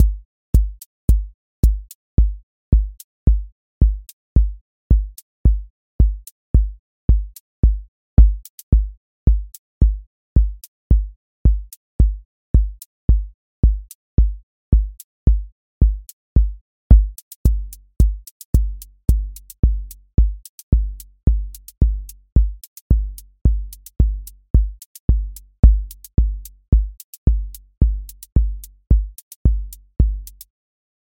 QA Listening Test house Template: four_on_floor
• macro_house_four_on_floor
• voice_kick_808
• voice_hat_rimshot
• voice_sub_pulse
Steady house groove with lift return